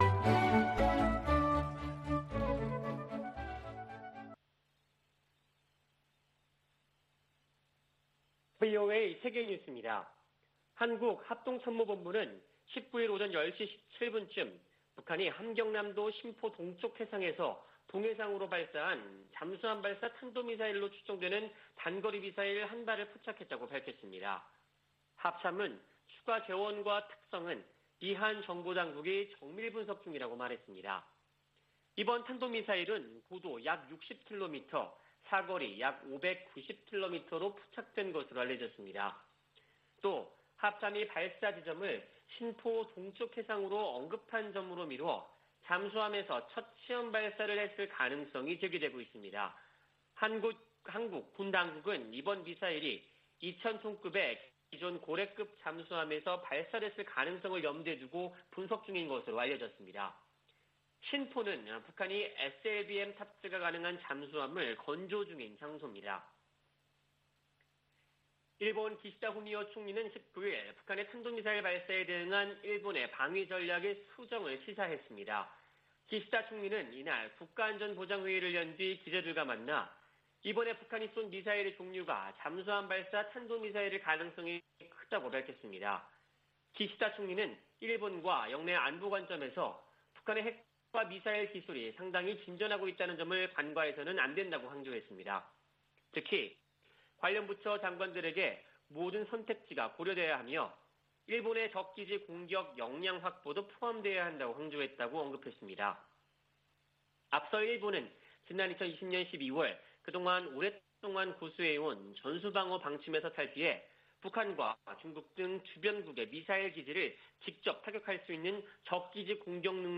VOA 한국어 아침 뉴스 프로그램 '워싱턴 뉴스 광장' 2021년 10월 20일 방송입니다. 한국 합동참모본부는 북한이 동해상으로 잠수함발사 탄도미사일(SLBM)로 추정되는 단거리 미사일 1발을 발사했음을 포착했다고 밝혔습니다. 미국과 한국, 일본 정보수장이 서울에서 만나 단거리 미사일 발사 등 북한 문제를 협의했습니다. 성 김 미 대북특별대표는 한국 정부가 제안한 종전선언에 관해 계속 논의할 것이라고 밝혔습니다.